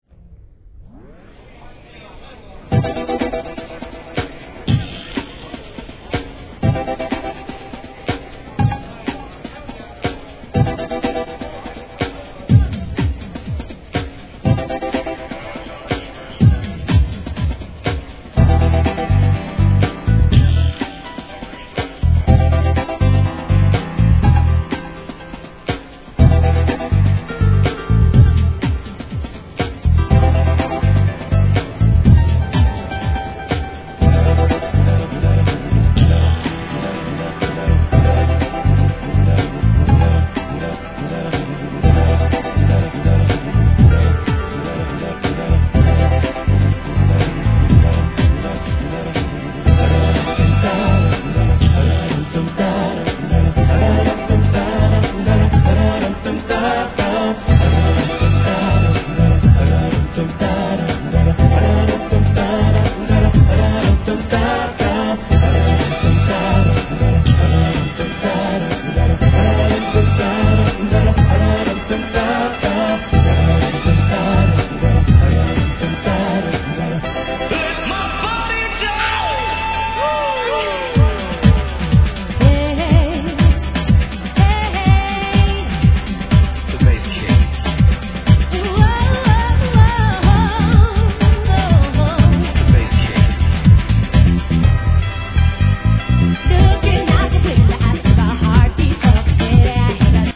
90'Sバレアリック・ハウス名曲！シンセも心地よいヴォーカル・ダンス・ナンバー!